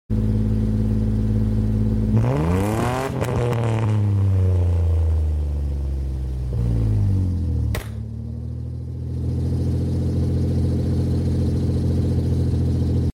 The sound of my 3.9 Litre V6 Dodge Dakota